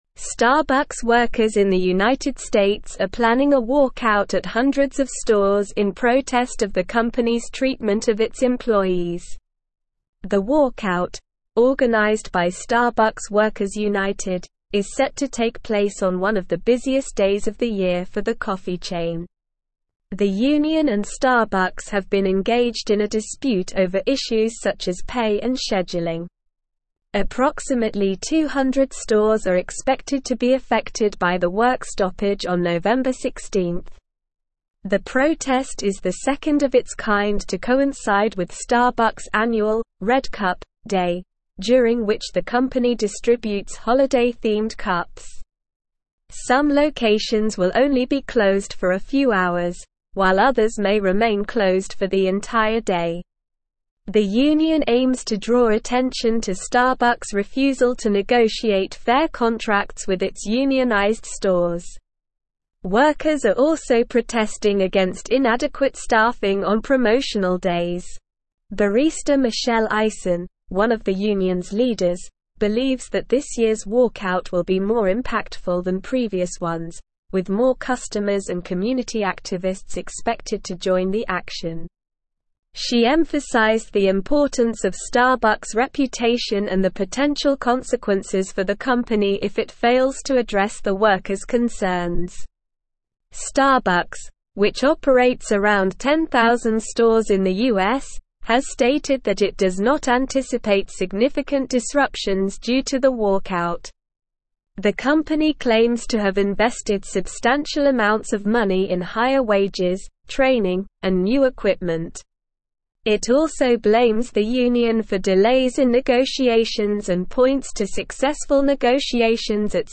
Slow
English-Newsroom-Advanced-SLOW-Reading-Starbucks-workers-plan-walkout-over-pay-and-conditions.mp3